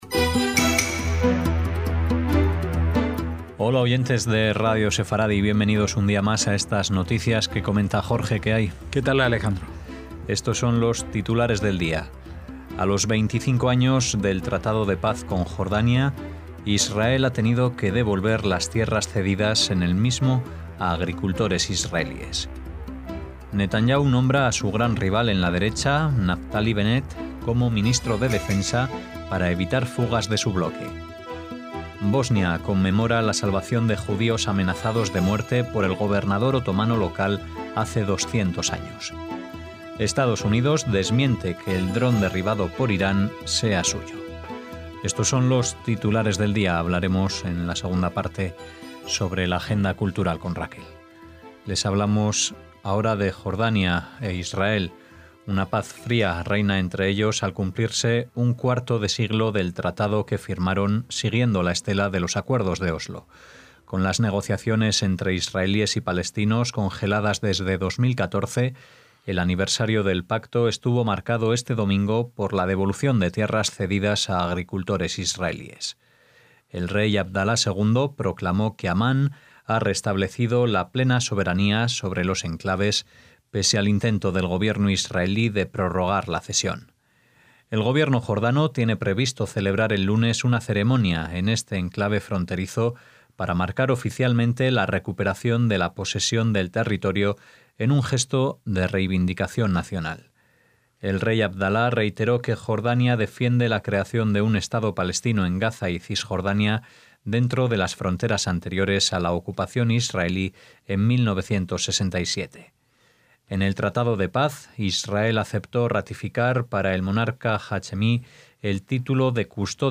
NOTICIAS - Titulares de hoy: A los 25 años del tratado de paz con Jordania, Israel ha tenido que devolver las tierras cedidas en el mismo a agricultores israelíes (en la imagen). Netanyahu nombra a su gran rival en la derecha, Naftali Bennett, como ministro de Defensa para evitar fugas de su bloque.